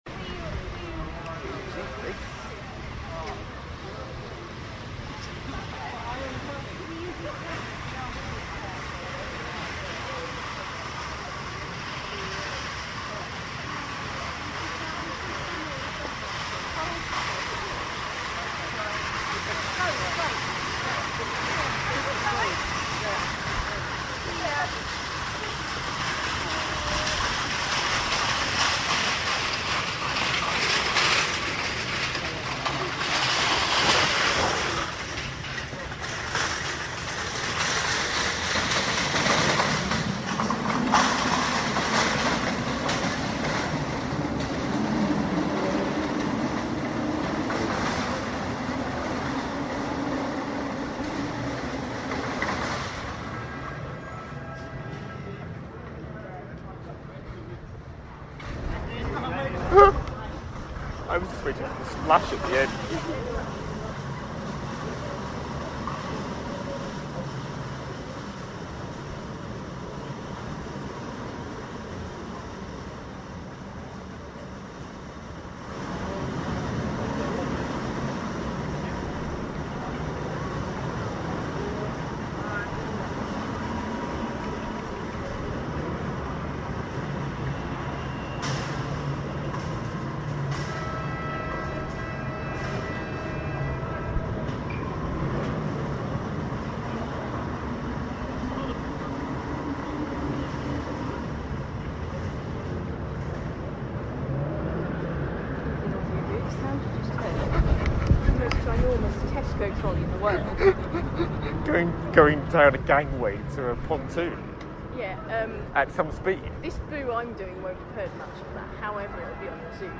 Great noise from a Convoy of Tesco Trolleys, my Attack Alarm and Big Ben striking half past